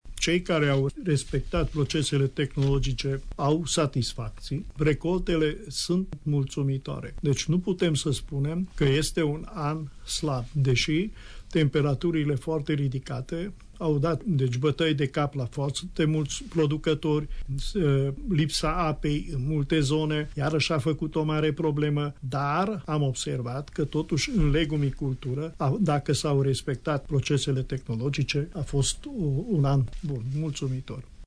specialist în domeniul legumiculturii şi pomiculturii